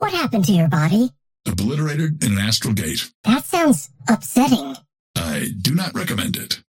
Dynamo and Viscous conversation 2